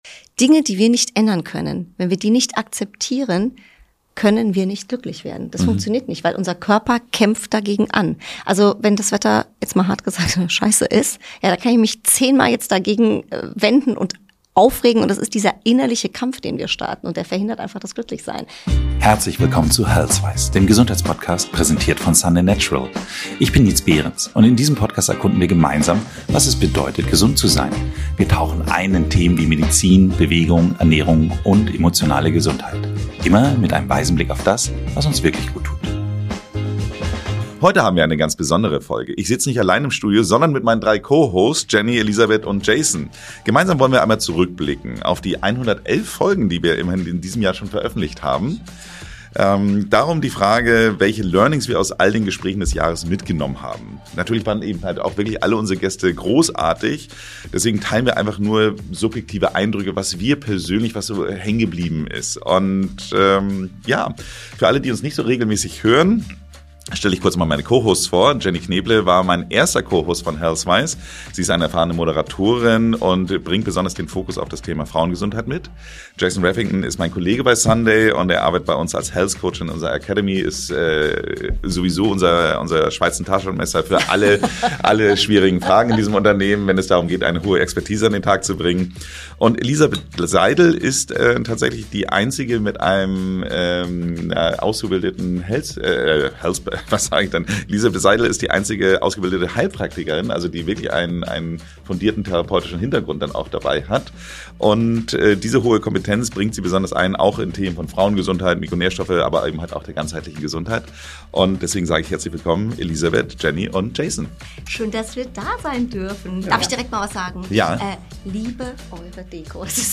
Vier Hosts, ein Jahr, 111 Folgen: Die stärksten Healthwise-Aha-Momente für deine Gesundheit im Weihnachts-Special.